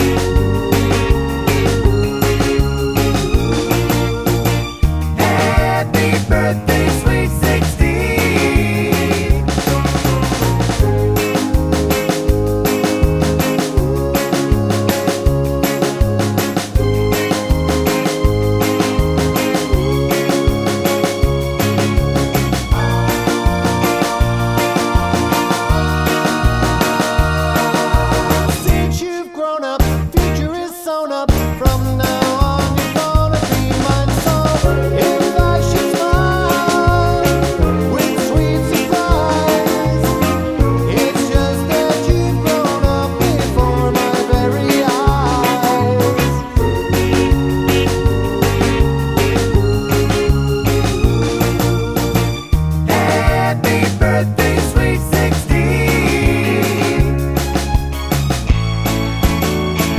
With High Two Part Harmony Pop